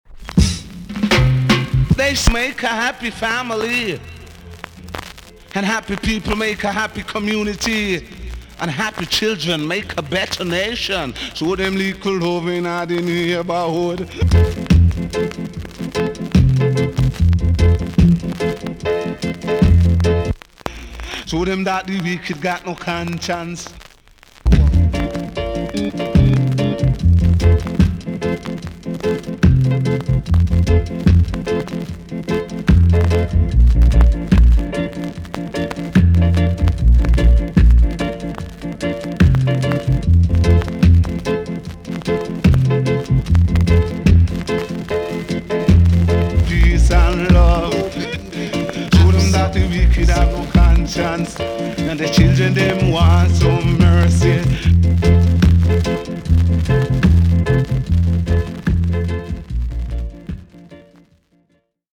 TOP >REGGAE & ROOTS
B.SIDE Version
VG ok チリノイズがあります。